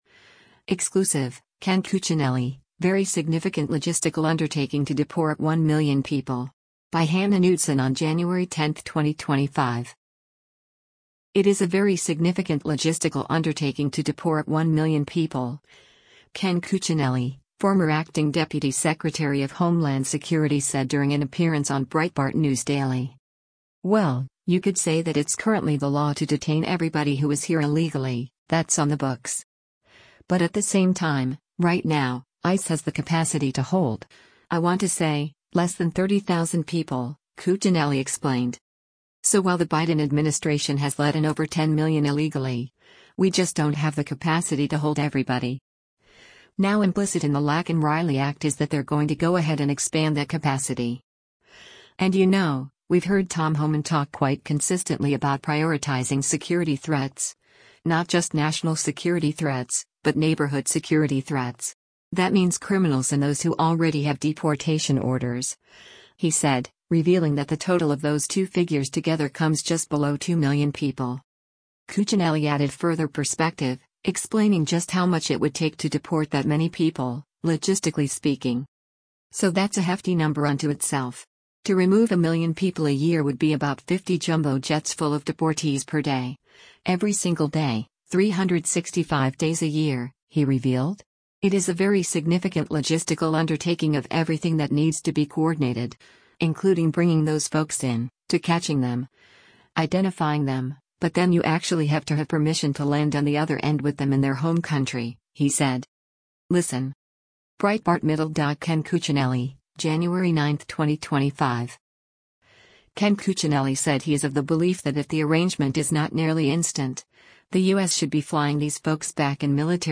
It is a “very significant logistical undertaking” to deport one million people, Ken Cuccinelli, former Acting Deputy Secretary of Homeland Security said during an appearance on Breitbart News Daily.